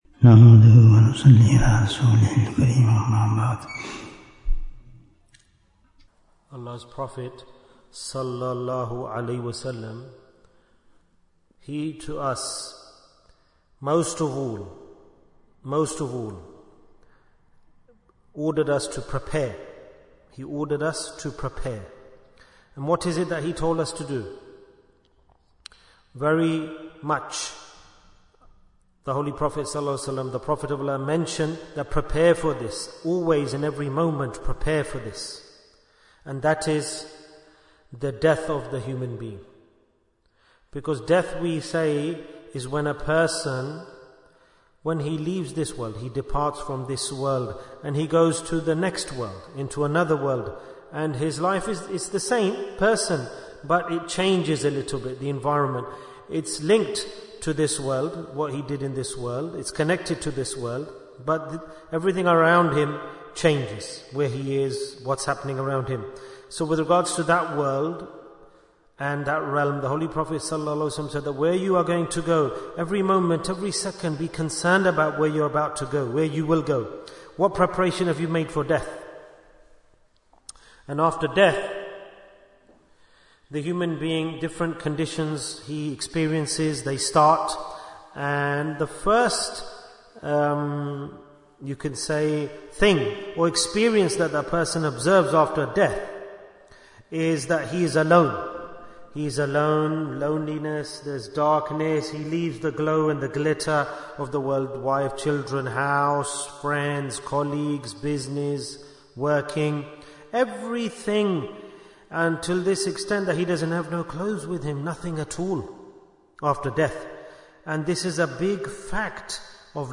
Majlis-e-Dhikr in Masjid Aishah Bayan, 21 minutes7th January, 2025